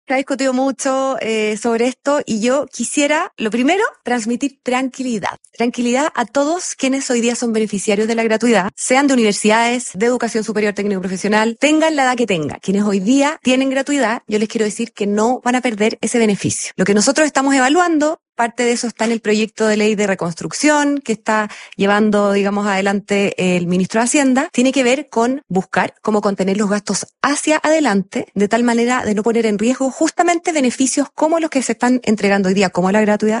En entrevista con ADN Hoy, la secretaria de Estado fue enfática en descartar cambios que afecten a quienes actualmente cuentan con gratuidad.